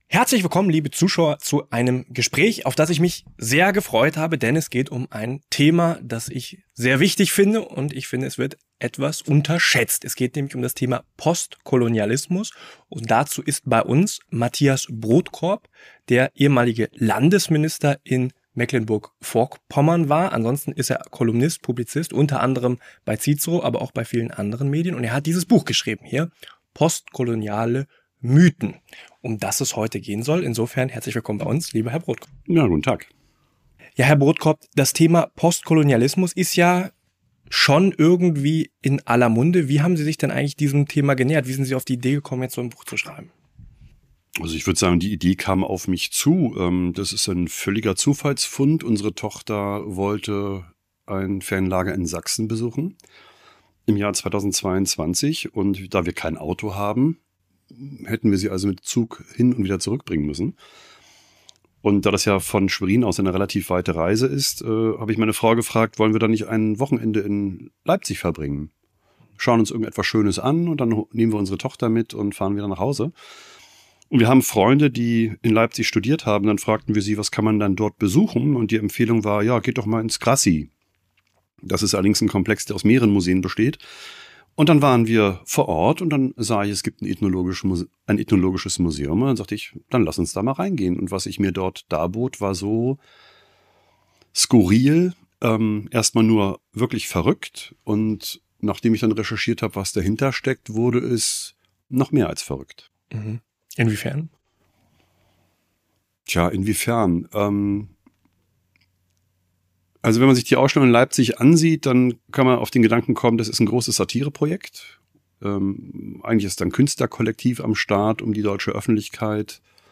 In seinem Buch „Postkoloniale Mythen“ entlarvt der Publizist Mathias Brodkorb die Instrumentalisierung der Geschichte. Im Apollo News-Gespräch geht es um die Wurzeln der postkolonialen Ideologie und darum, was sie so gefährlich macht.